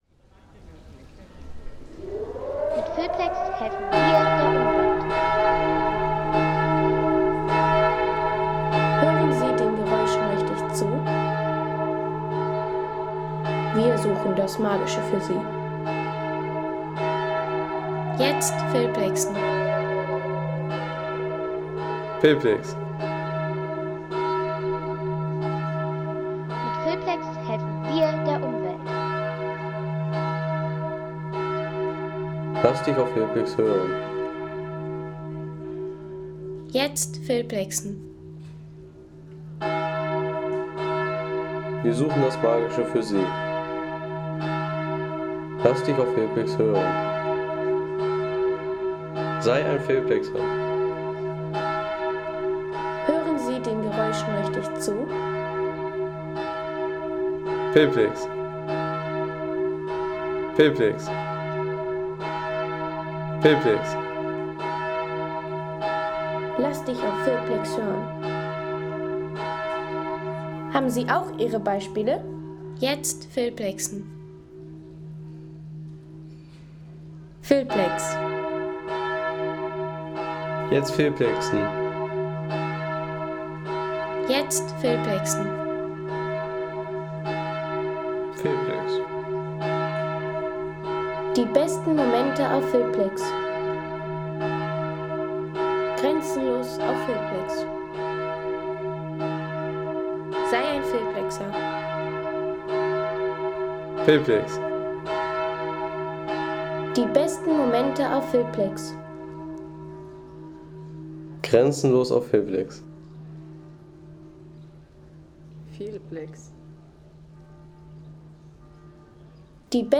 Innsbrucker Dom Glockengeläut | Feelplex
Majestätisches Glockengeläut des Innsbrucker Doms zu St. Jakob mit historischem Klang und Stadtraum-Atmosphäre.
Majestätisches Geläut des Innsbrucker Doms zu St. Jakob, begleitet vom markanten Dauerton der Stadtsirene.